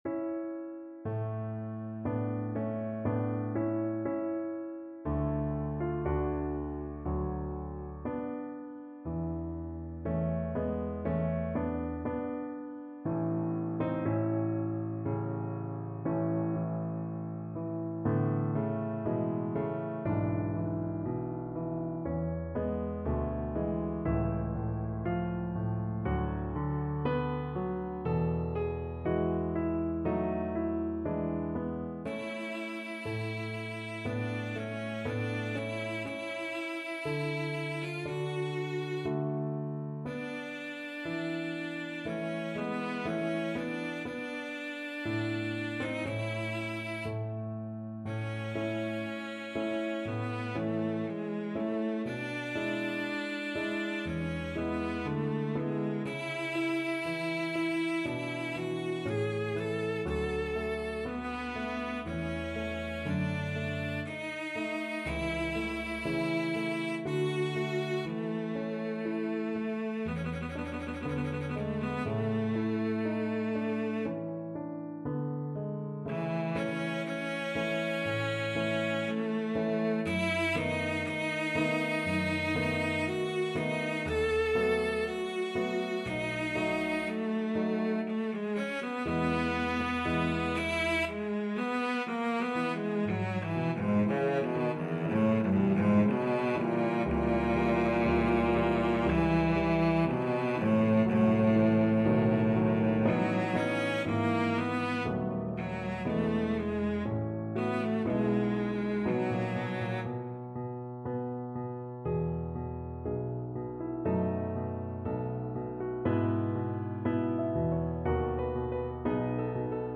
Adagio = c. 60
Classical (View more Classical Cello Music)